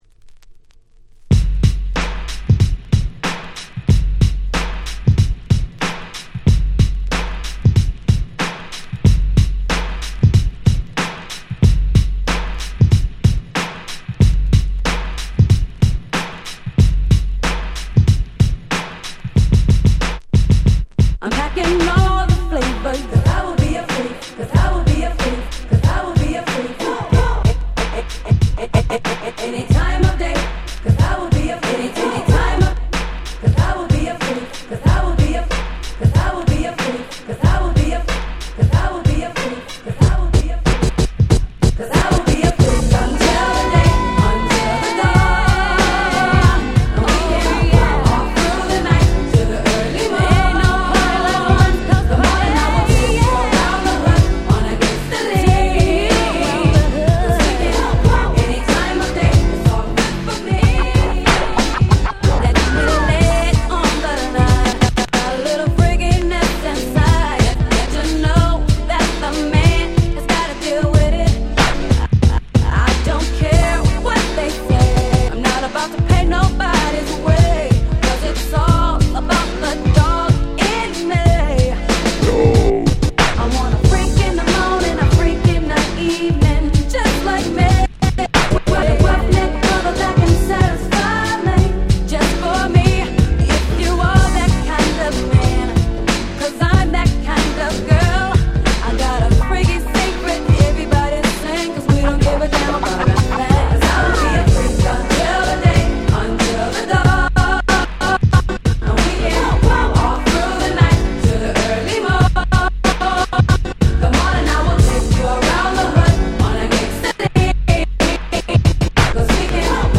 微妙に使い難かったオリジナルをバッチリ使い易いフロア仕様に！！